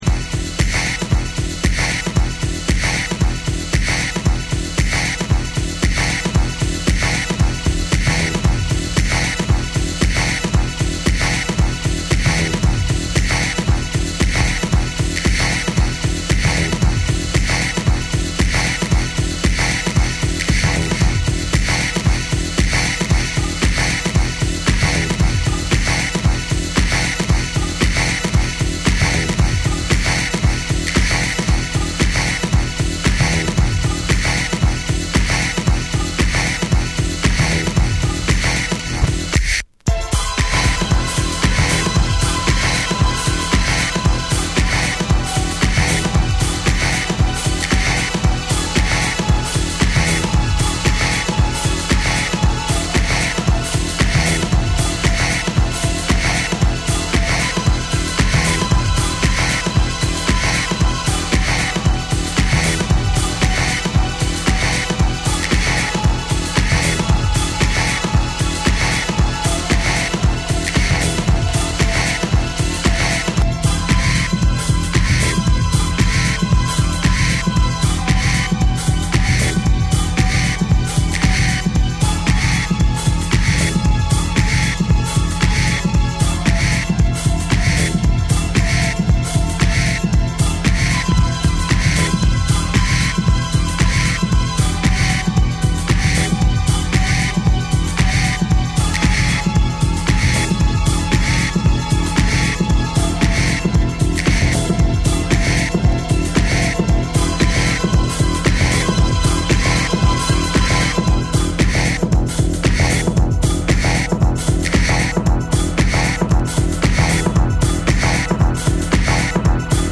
TECHNO & HOUSE / DETROIT